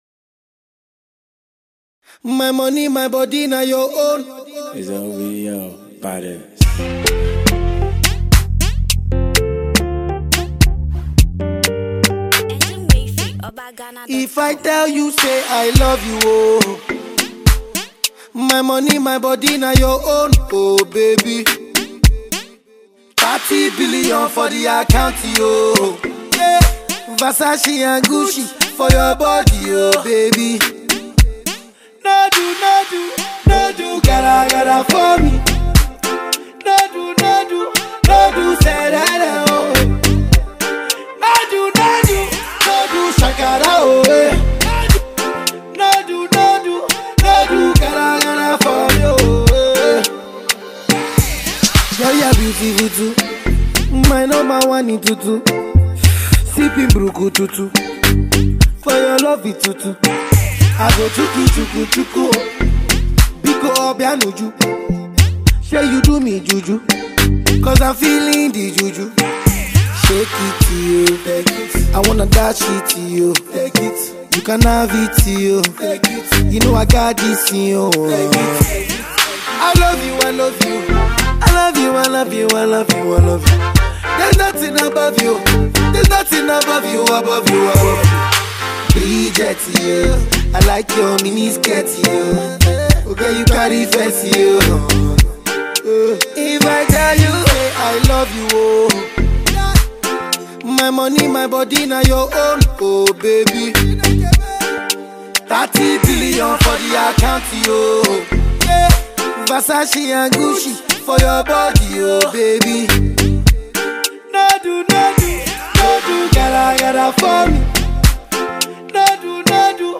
Naija Music
Afropop